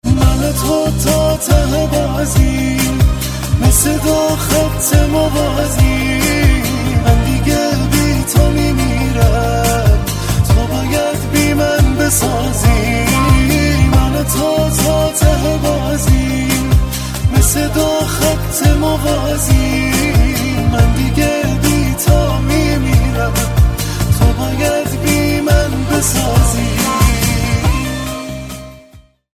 رینگتون احساسی و باکلام